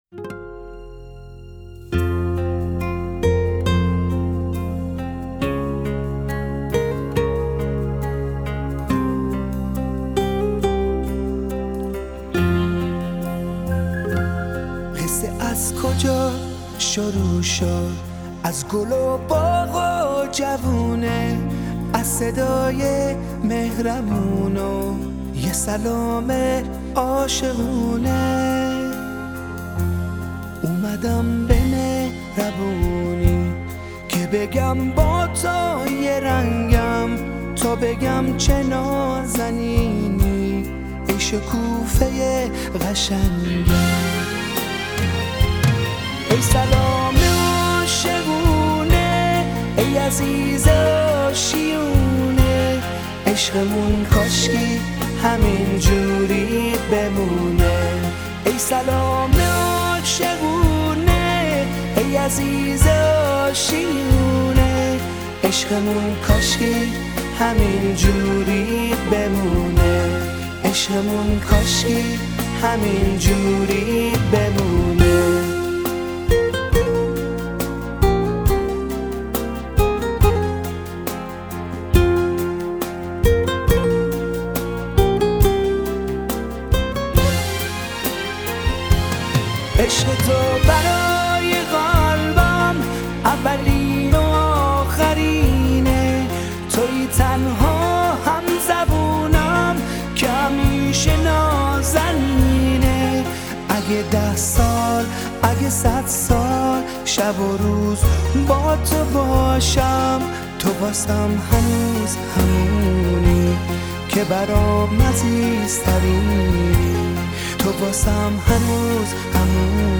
آهنگ تانگو عروسی